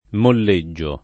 molleggio [ moll %JJ o ] s. m.; pl. ‑gi